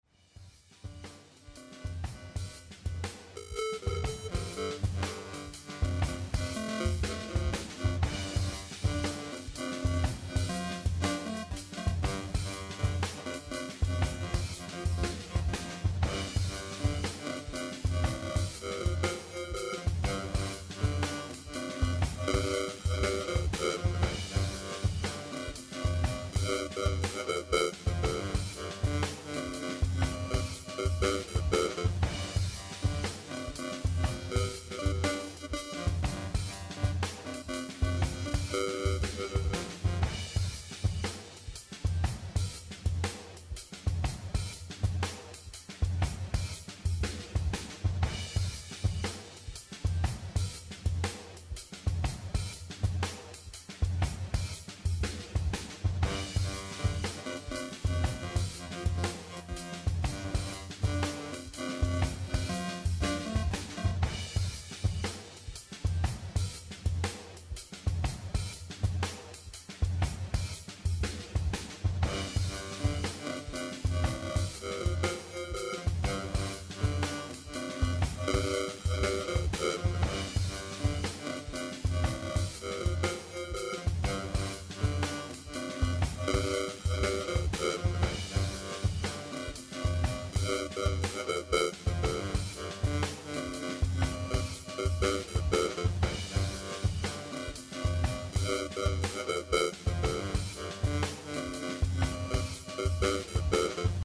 If you're into Ring-Mods and Multiplying Octavers you may like the sound of this thing ... when the sampling frequency is set high enough chords come out sounding not so screwed up as they do with the two other modulator category circuits ... by varying the Sampling Freek knob we indirectly control the amount of wave-screwing that occurs ...